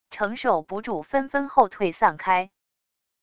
承受不住纷纷后退散开wav音频生成系统WAV Audio Player